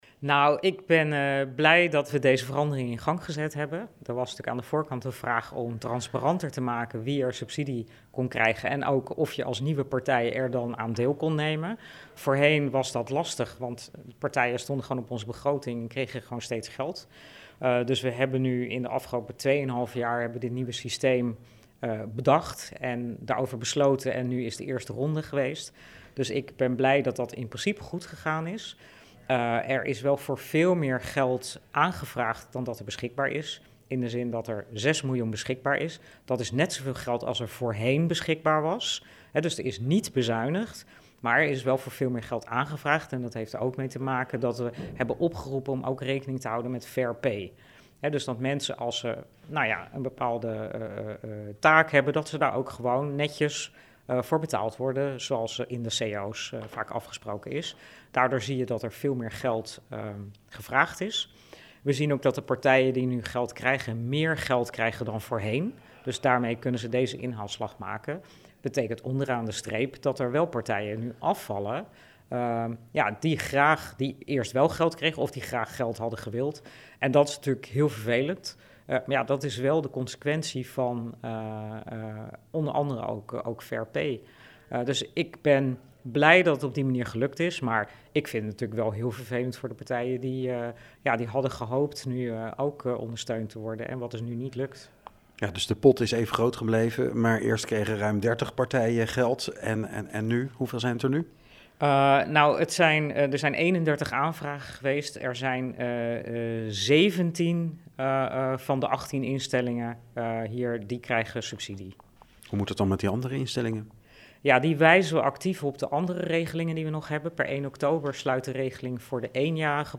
Verslaggever
in gesprek met wethouder cultuur Yvonne van Delft